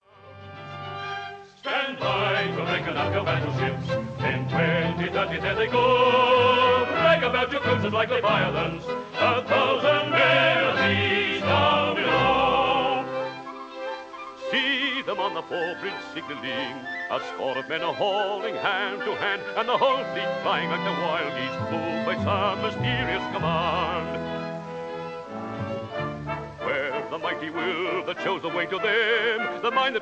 This is a song
baritone